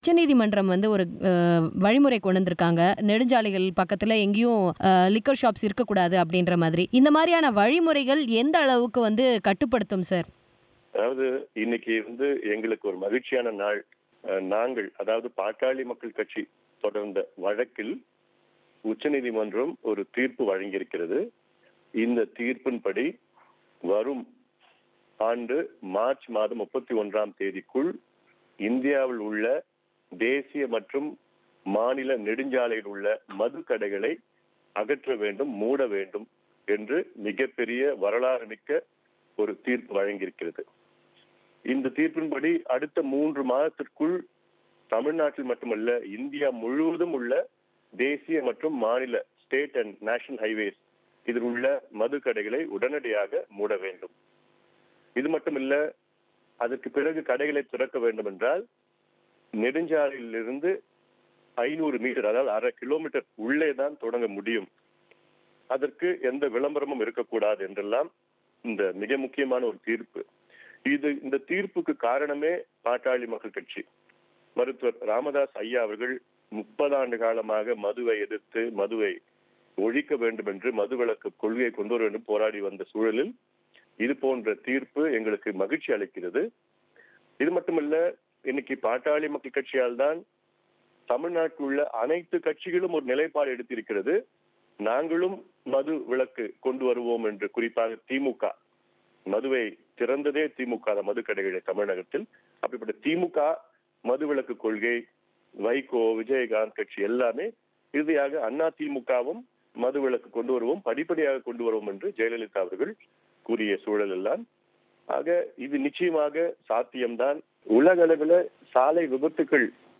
நெடுஞ்சாலைகளில் உள்ள மதுக்கடைகளை அகற்ற வேண்டும் என்ற உச்சநீதிமன்றத்தின் தீர்ப்பு குறித்து பாமக தலைவர் அன்புமணியின் பேட்டி